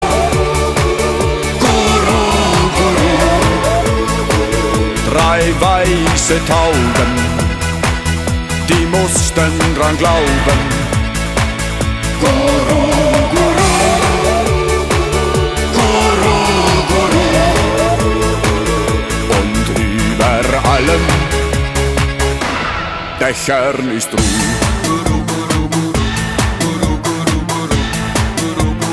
Besetzung: Blasorchester
Tonart: B-Dur